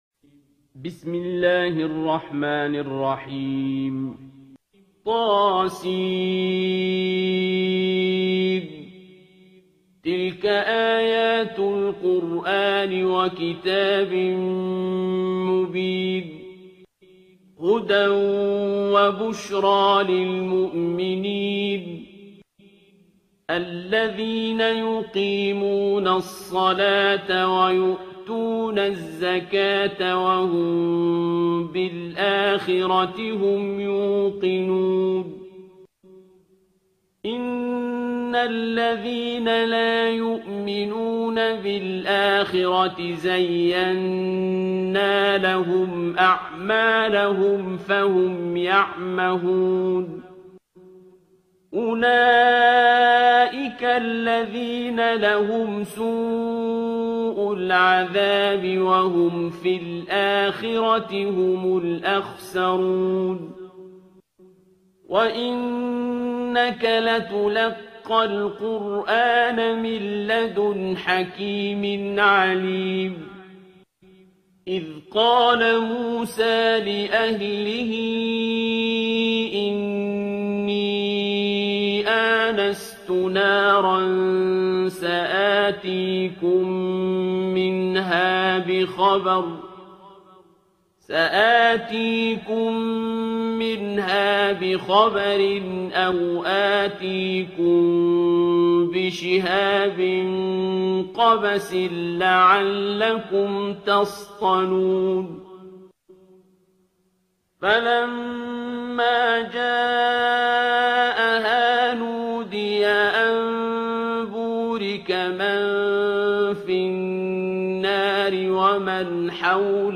ترتیل سوره نمل با صدای عبدالباسط عبدالصمد
027-Abdul-Basit-Surah-Al-Naml.mp3